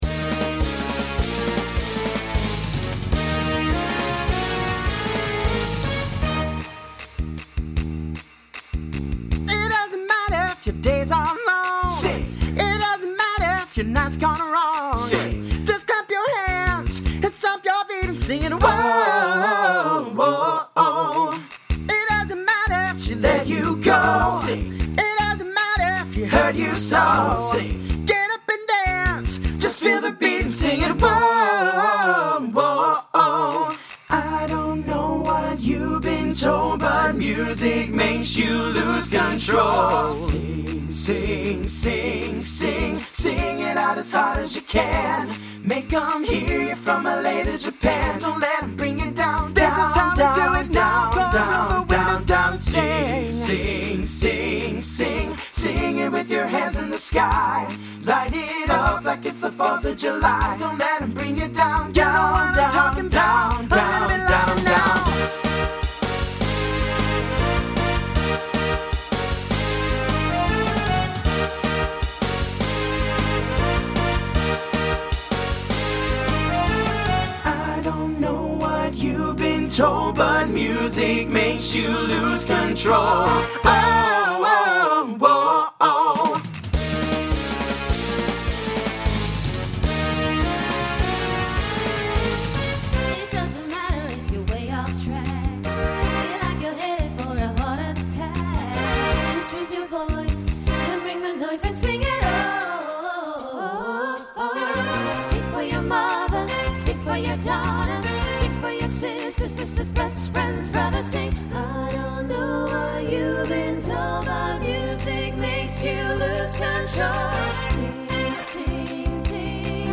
guys/girls feature
Voicing Mixed Instrumental combo
Pop/Dance